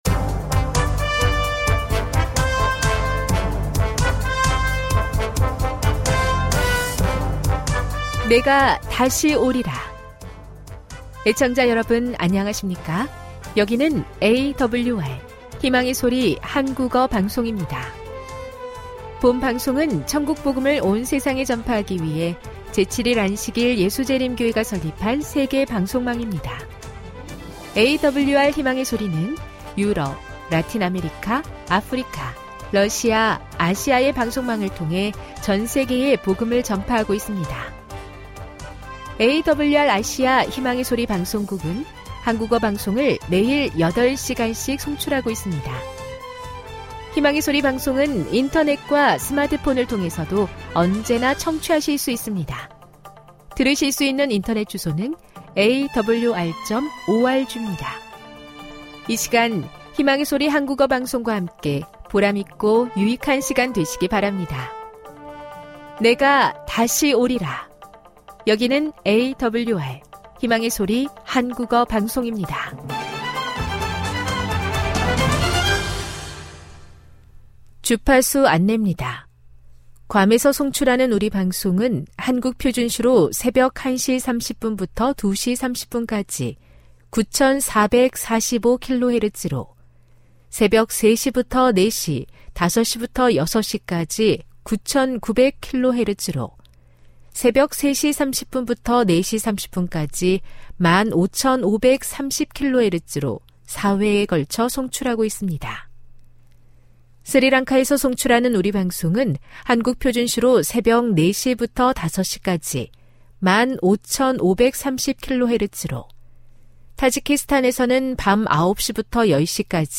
설교, 난해 성경절해설. 안식일대예배.